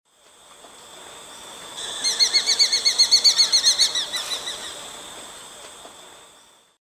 Águila Viuda Halcón Negro Chico
Black-and-white Hawk-eagle Bat Falcon